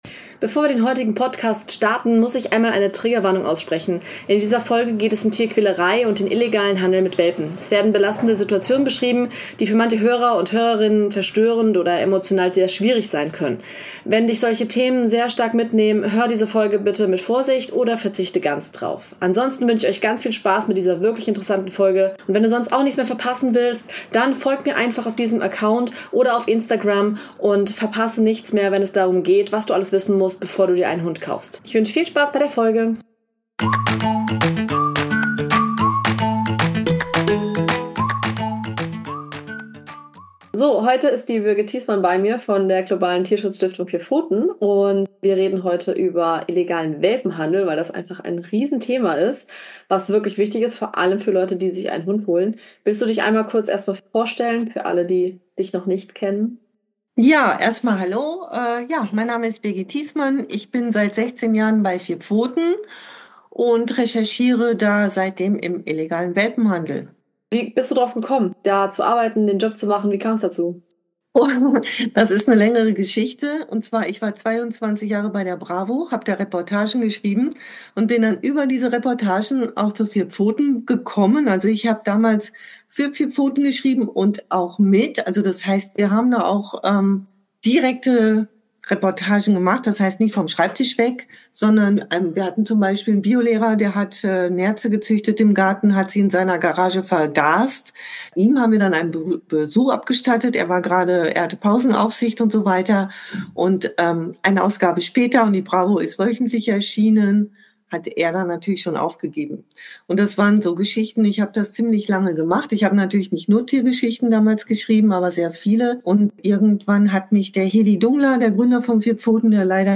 Illegaler Welpenhandel - Wenn der Traum vom Hund zum Albtraum wird – Zu Gast